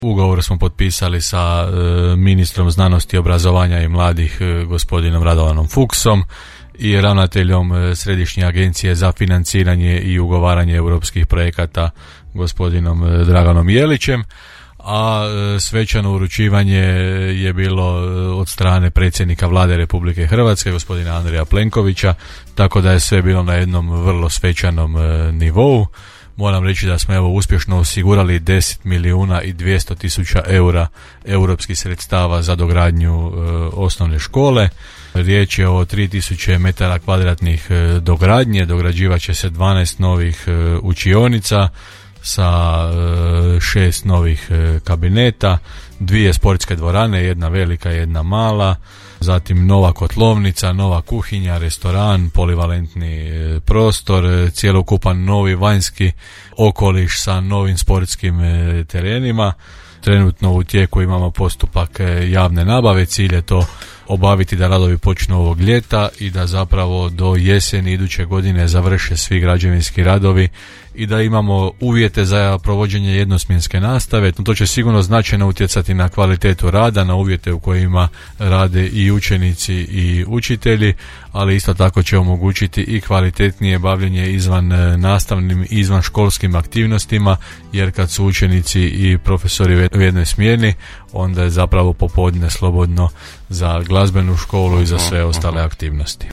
– rekao je gradonačelnik Janči u emisiji Gradske teme, koja je dostupna na YouTube kanalu Podravskog radija.